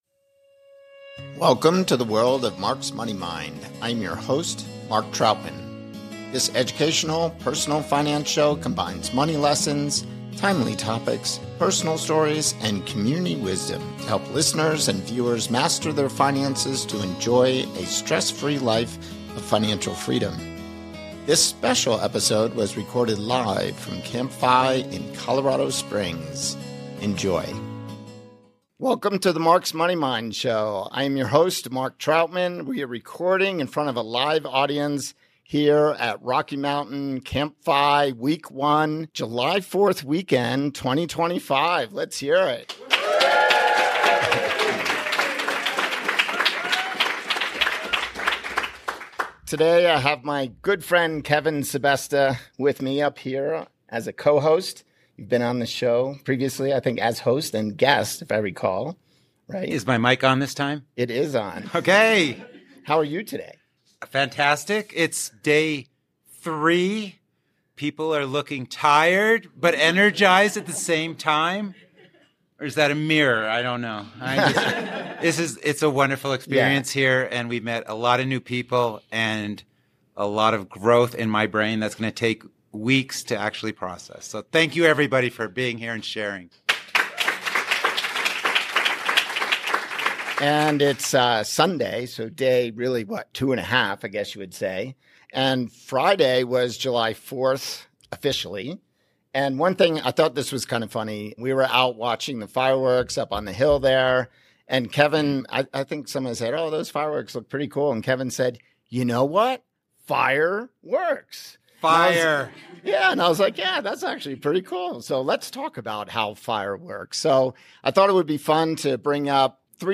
F*I*R*E Works at CampFI Rocky Mountain | Episode 049